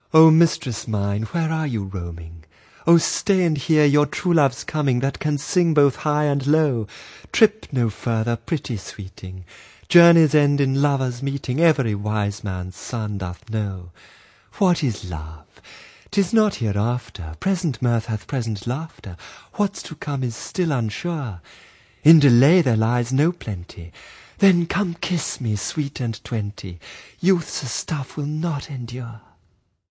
Reading 3: